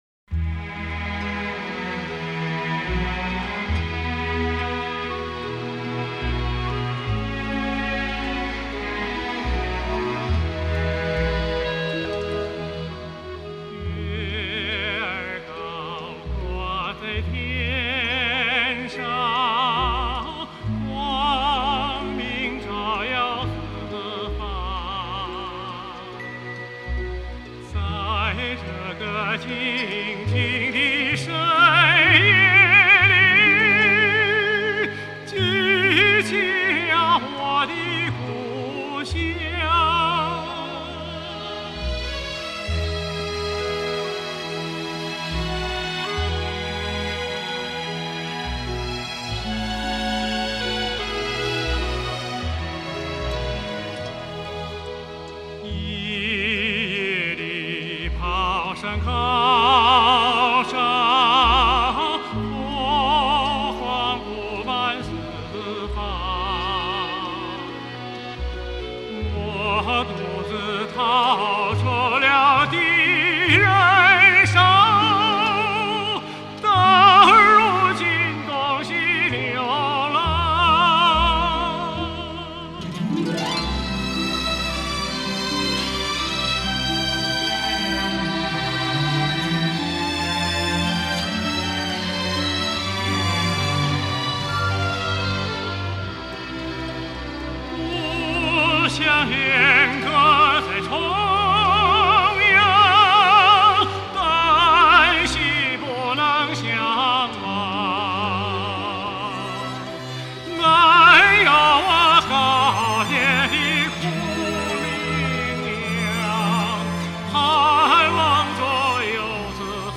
1953年调至中央乐团任独唱演员，在舞台实践中，被人们公认是我国著名男高音之一，曾名噪乐坛。